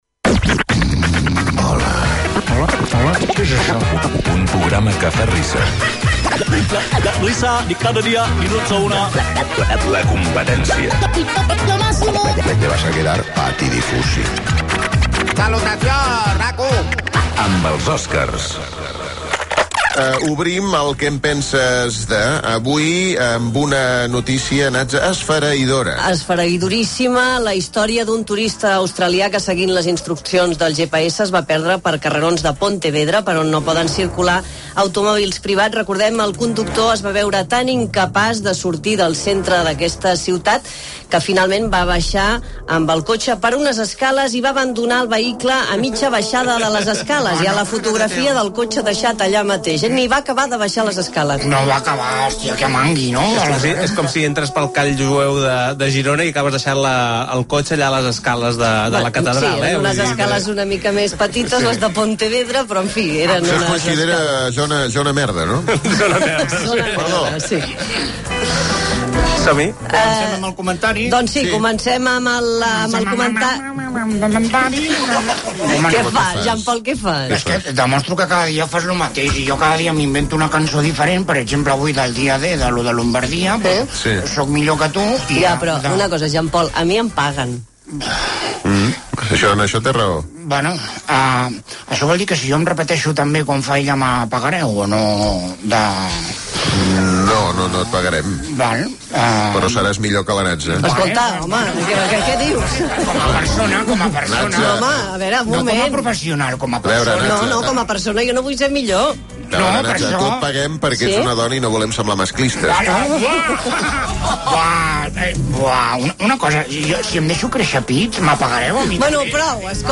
Indicatiu del programa, secció Què penses de…? Un conductor australià,que seguia el GPS, va abandonar el seu cotxer al centre de Pontevedra. Comentaris humorístics sobre aquest tema i opinions de l'audiència
Entreteniment